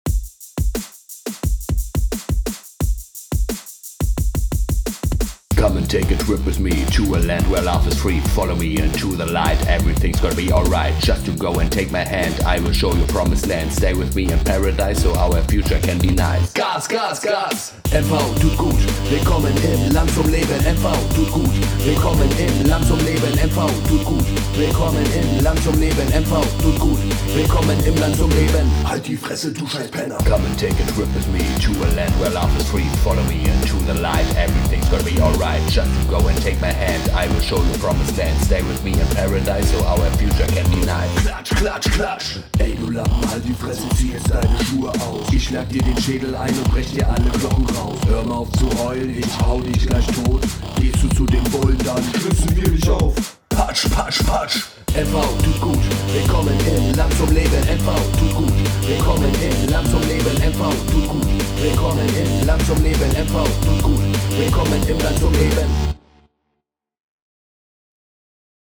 Kategorie: Stückssongs und Sounds